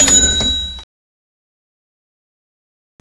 bell2.wav